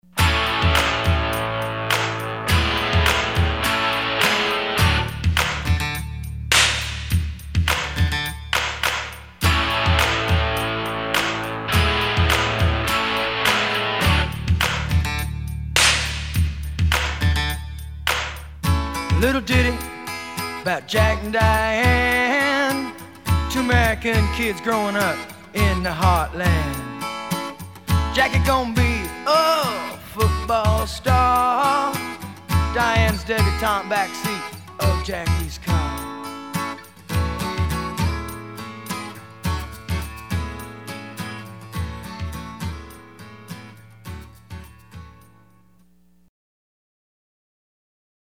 OLD SCHOOL ROCK no. 3